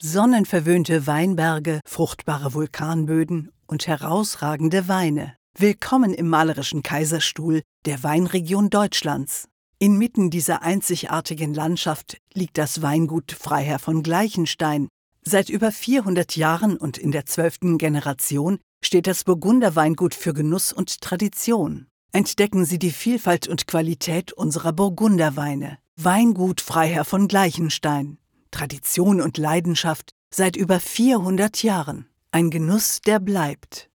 Mit ihrer tiefen, samtigen Charakterstimme gibt die Sprecherin jedem Script einen individuellen Touch.
Sprechprobe: Werbung (Muttersprache):
With her deep, velvety character voice, the speaker gives every script an individual touch.
Writers and listeners alike appreciate the varied realisation of her texts and the outstanding audio quality of the recordings produced in her own studio.